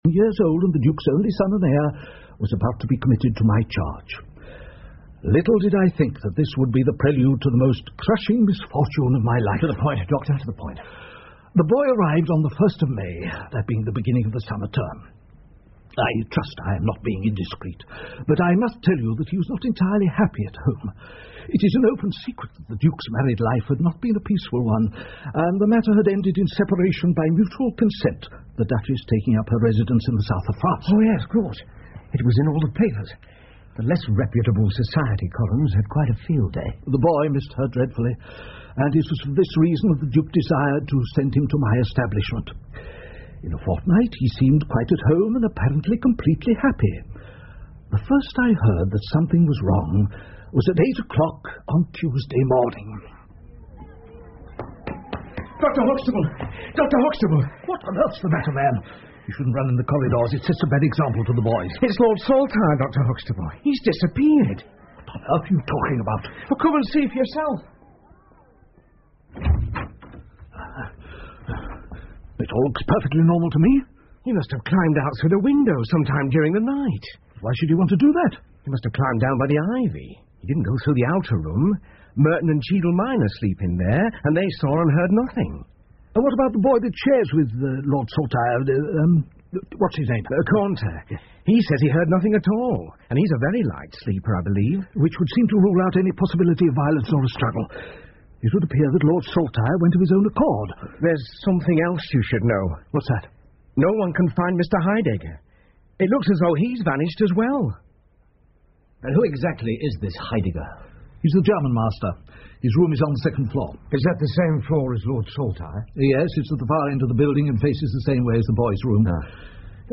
福尔摩斯广播剧 The Priory School 2 听力文件下载—在线英语听力室